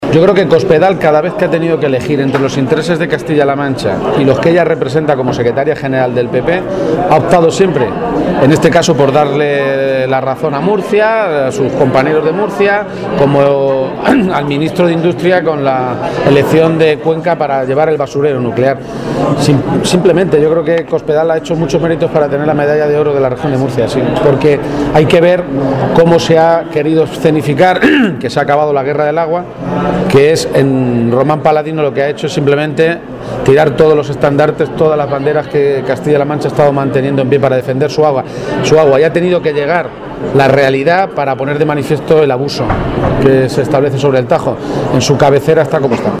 El candidato socialista a la Presidencia de Castilla-La Mancha participó anoche en la cena solidaria del PSOE de Guadalajara
García-Page hizo estas declaraciones durante la cena de Navidad del PSOE de esta provincia, a la que asistieron cerca de 400 personas militantes y simpatizantes, y que nuevamente tuvo un carácter solidario con la recogida de juguetes que serán entregados a familias con dificultades económicas.